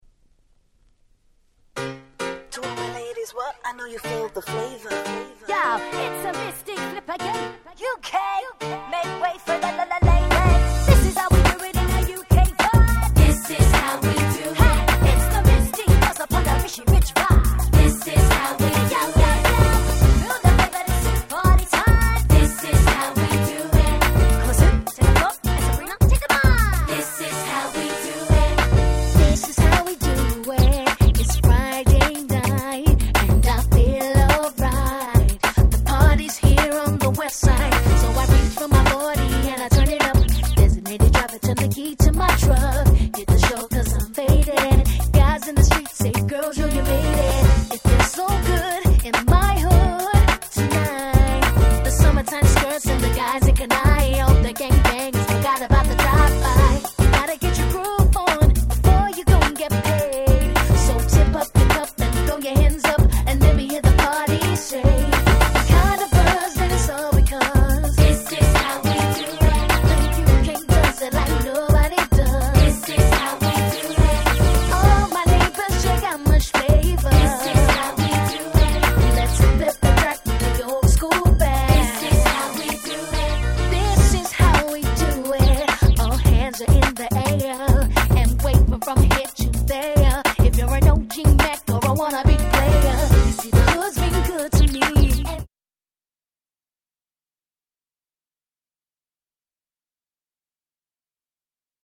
【Media】Vinyl 12'' Single